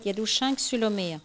Localisation Notre-Dame-de-Riez
Catégorie Locution